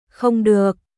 Không đượcNot allowed許されない、ダメコン ドゥオック